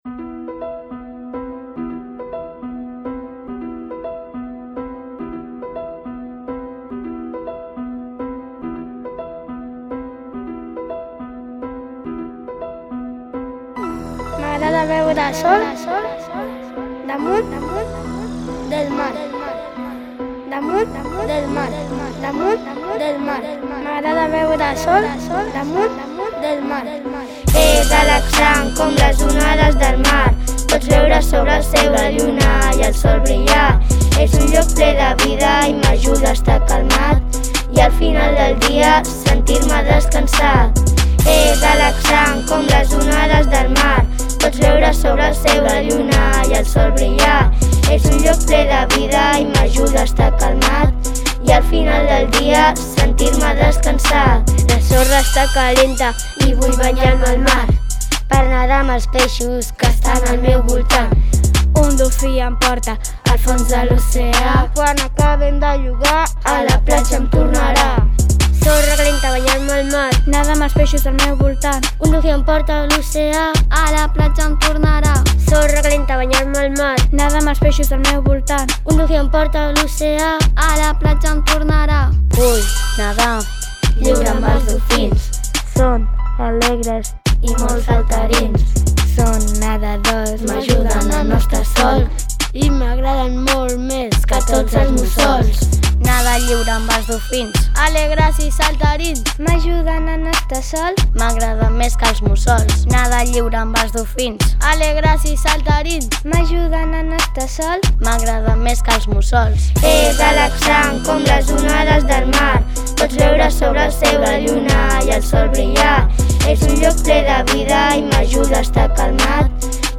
Durant aquestes sessions, els infants de 4t han pogut pensar i crear la lletra, per interpretar dues cançons en català amb una base rítmica de rap.